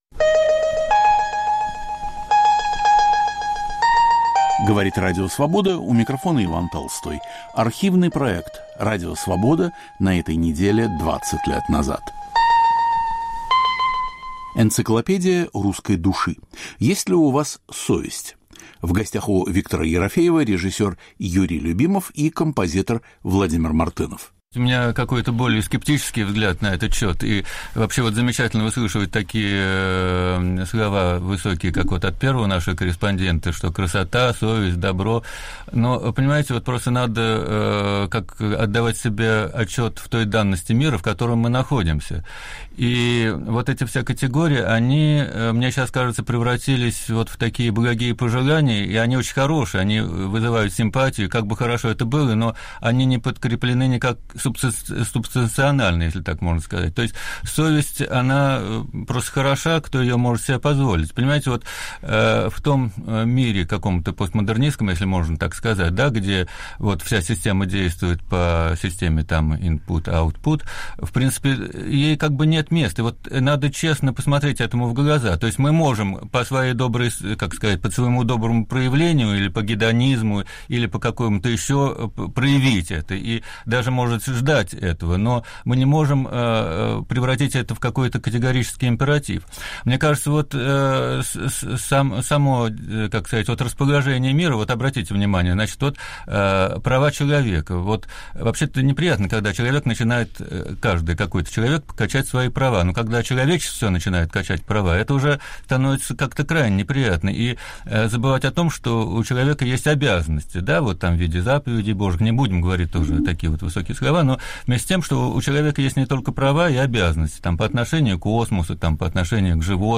В гостях у Виктора Ерофеева режиссер Юрий Любимов и композитор Владимир Мартынов.